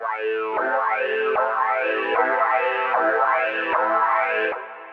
Because of this, even if it is digital synthesis, it sounds "warm".
All these demos are recorded directly from the ZynAddSubFX without audio processing with another program (well, exeption cutting/ OGG Vorbis compressing).